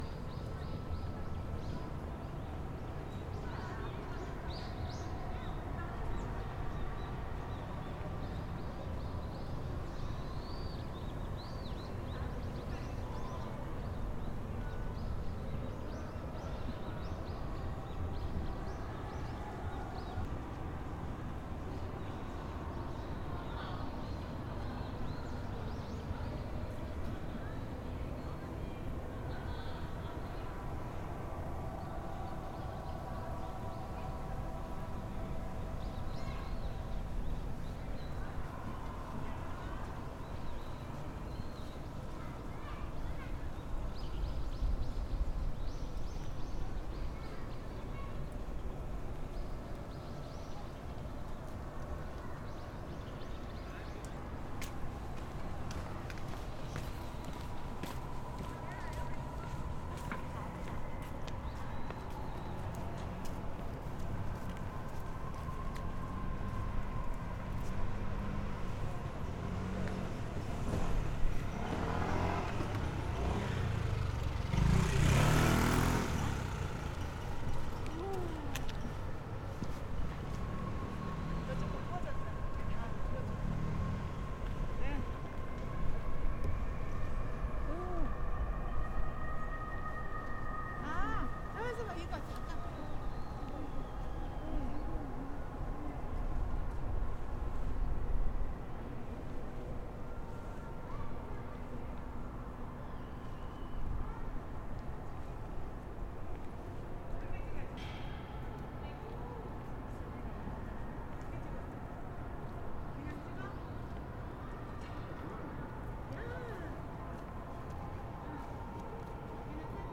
앞마당01.mp3